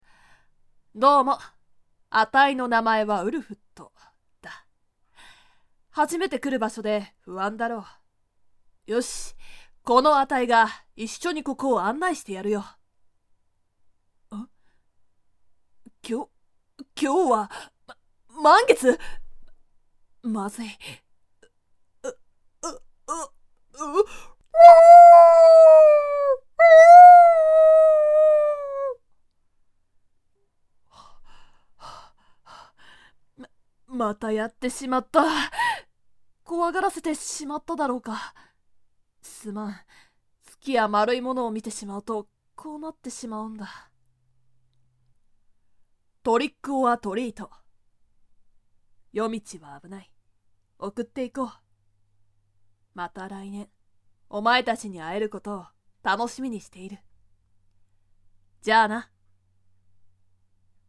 狼の女の子
月や丸いものを見てしまうと思わず遠吠えをしてしまう